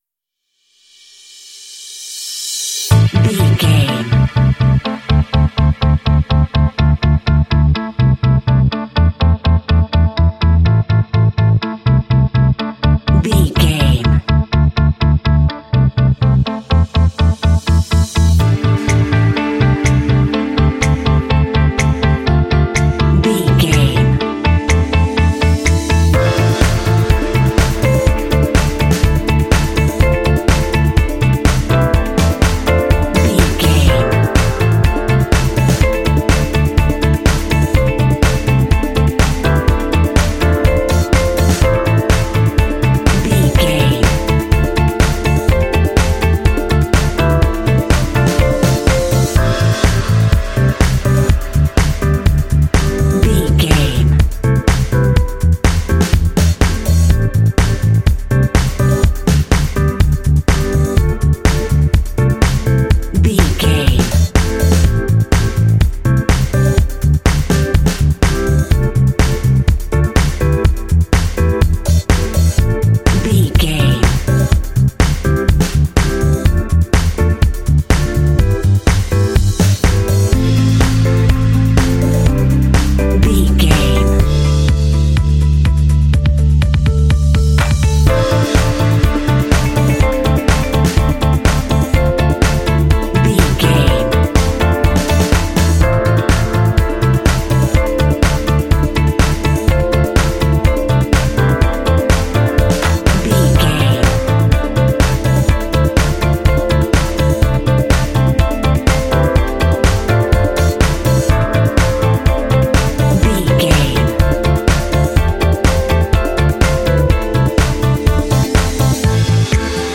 Uplifting
Aeolian/Minor
futuristic
groovy
high tech
electric guitar
percussion
drums
bass guitar
electric piano
contemporary underscore
indie